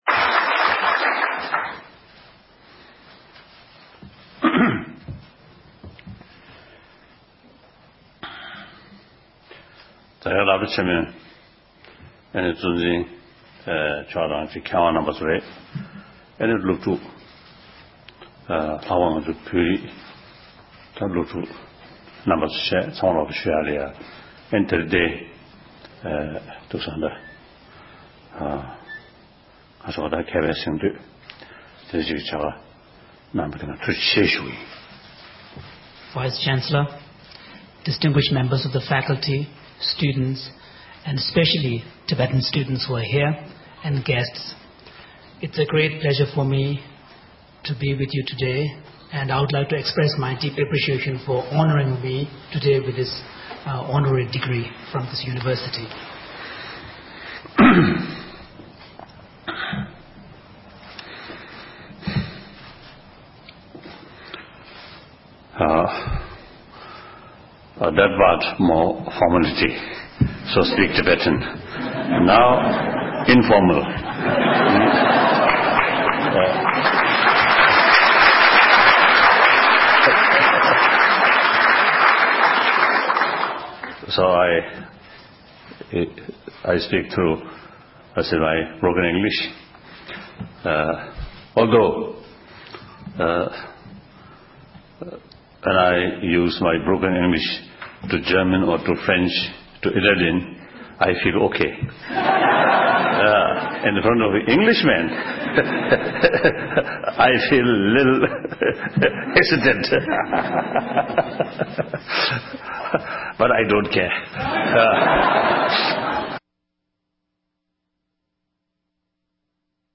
སྒྲ་ལྡན་གསར་འགྱུར། སྒྲ་ཕབ་ལེན།
༄༅༎བོད་ཀྱི་ཆོས་སྲིད་གཉིས་ཀྱི་དབུ་ཁྲིད་སྤྱི་ནོར་༸གོང་ས་༸སྐྱབས་མགོན་ཆེན་པོ་མཆོག་འཇར་མེ་ནིའི་མཛད་འཆར་ལེགས་པར་གྲུབ་རྗེས་དབྱིན་ཡུལ་དུ་ཞབས་སོར་འཁོད་ཡོད་པ་དང༌། དབྱིན་ཇིའི་རྒྱལ་ས་ལོན་ཌོན་གྲོང་ཁྱེར་གྱི་ཆེས་མཐོའི་གཙུག་ལག་སློབ་གཉེར་ཁང་ནས་༸གོང་ས་མཆོག་ལ་ཆེ་བསྟོད་ཕྱག་འཁྱེར་ཕུལ་སྐབས་དགེ་ལས་སློབ་གཉེར་བར་བཀའ་སློབ་བསྩལ་བ་དེ་གསན་རོགས་ཞུ༎